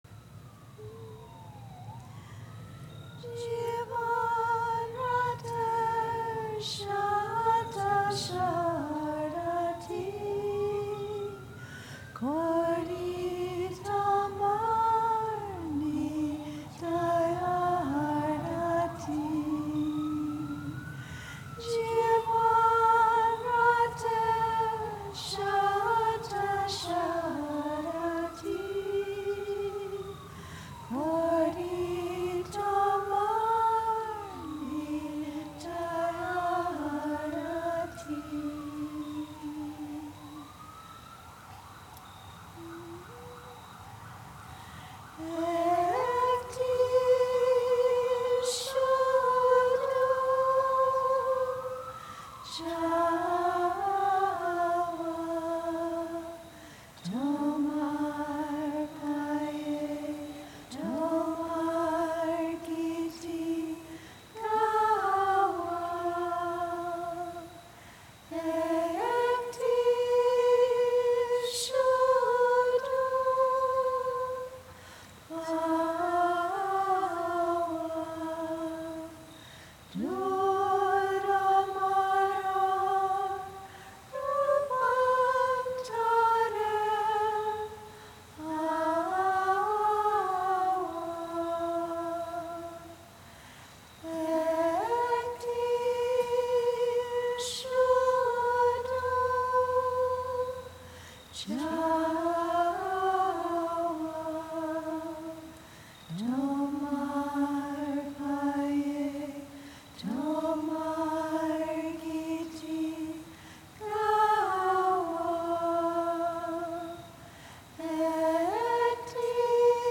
Liebe Hörerinnen und Hörer, heute teilen wir mit Ihnen eine Auswahl von seelenvollen musikalischen Aufführungen von Sri Chinmoys Schülern.
Diese meditativen Gesangs- und Musikdarbietungen wurden während einer besonderen Veranstaltung anlässlich des Jahrestages von Sri Chinmoys Mahasamadhi (Bezeichnung für den Tod eines spirituellen Meisters) am 11.